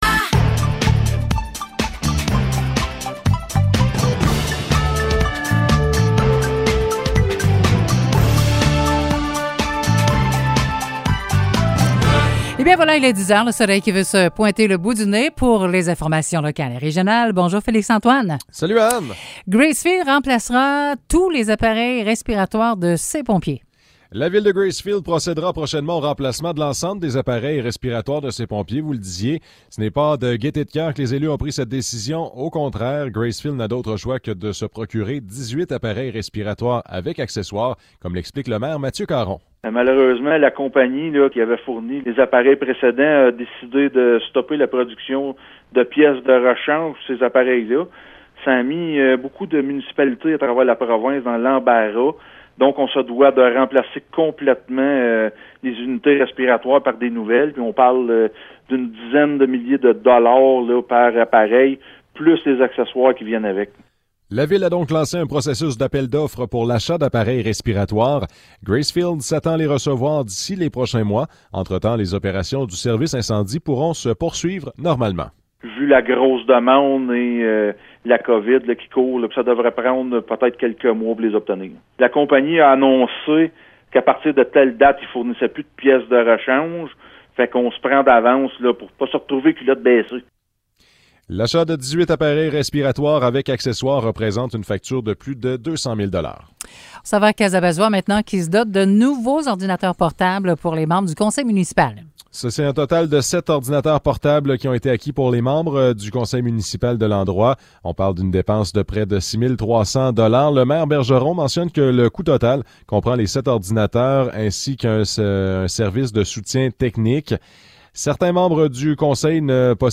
Nouvelles locales - 3 février 2022 - 10 h